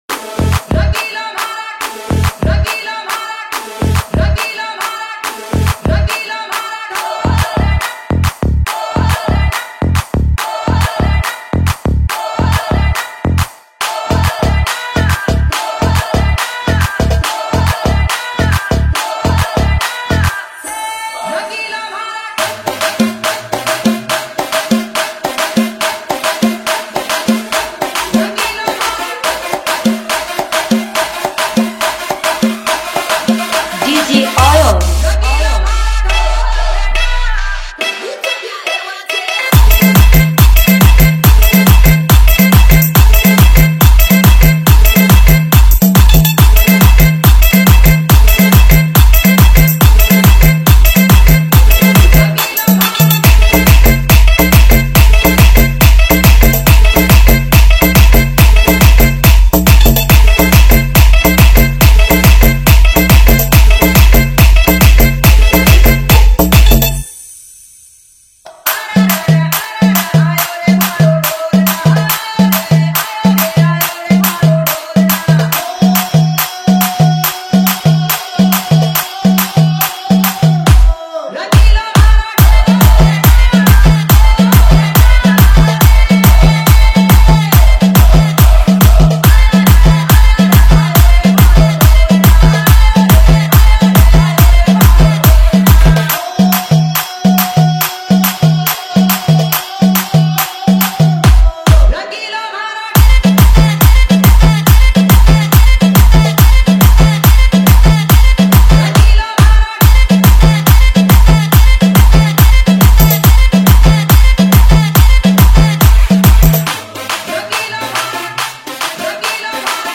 Category : Mashup Remix Song